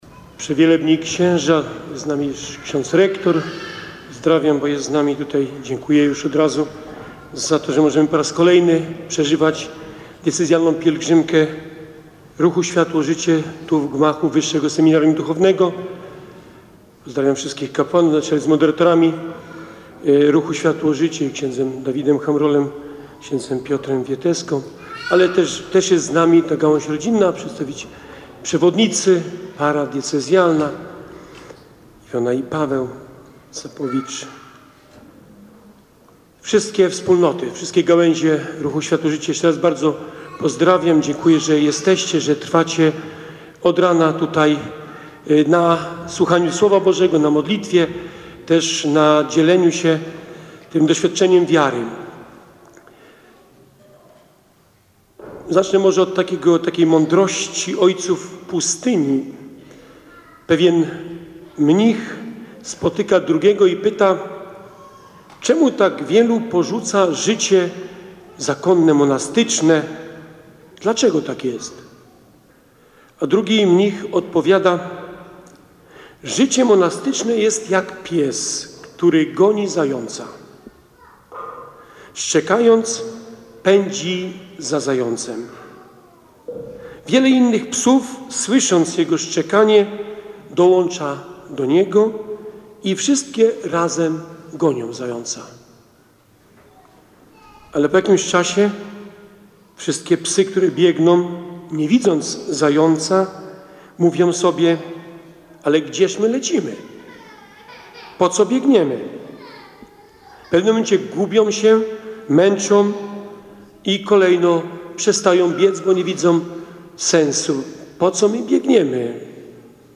Bp Krzysztof Włodarczyk w homilii wygłoszonej podczas Diecezjalnej Pielgrzymki Ruchu Światło-Życie podjął temat wytrwałości w podążaniu do celu.